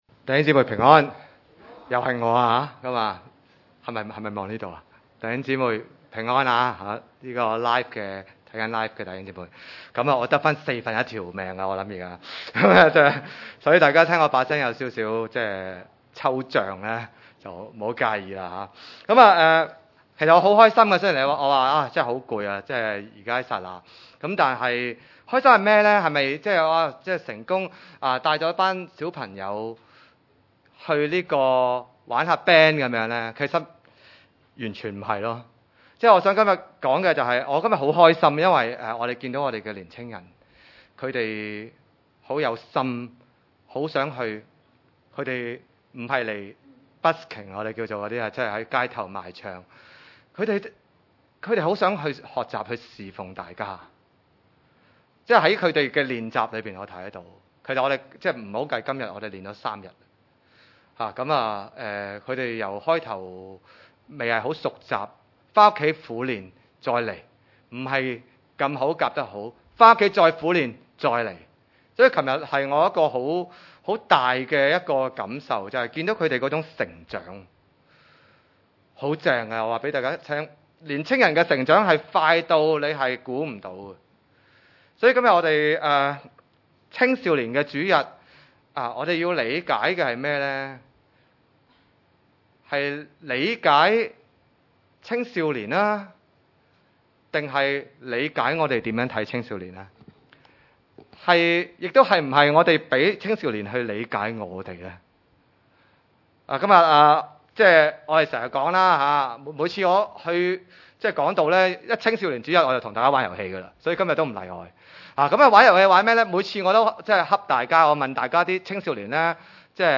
經文: 哥林予前書三:1-8節 崇拜類別: 主日午堂崇拜 3:1 弟兄們、我從前對你們說話、不能把你們當作屬靈的、只得把你們當作屬肉體、在基督裡為嬰孩的。